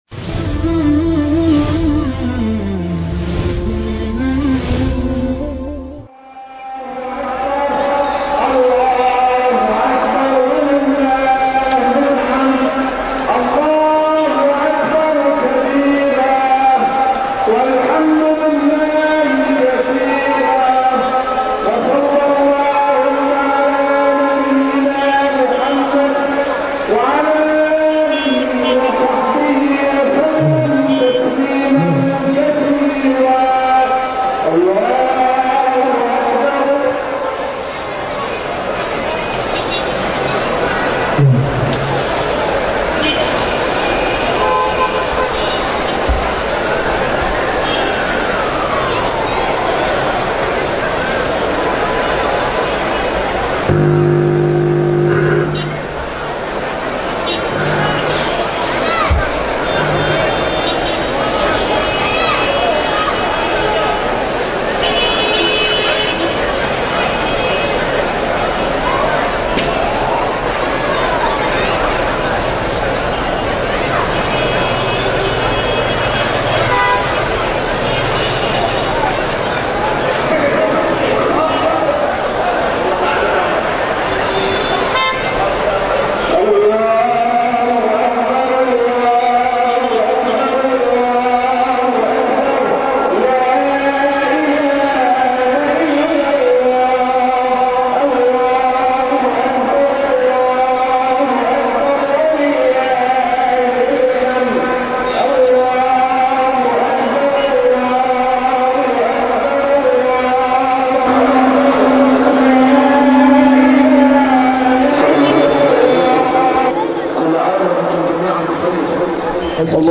صلاة عيد الفطر 1439هـ